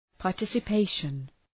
Προφορά
{pɑ:r,tısə’peıʃən}